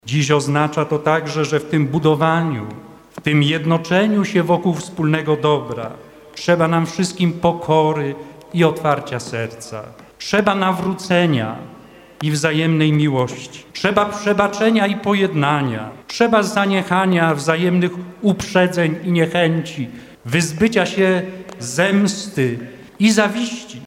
Abp Wojciech Polak przewodniczy głównej Sumie odpustowej w uroczystość Matki Bożej Częstochowskiej.
Abp Polak podkreślał w kazaniu, że „wszyscy nosimy w sobie całe to dziedzictwo, któremu na imię Polska i na nas wszystkich ciąży wielki i święty obowiązek budowania sprawiedliwej jedności”.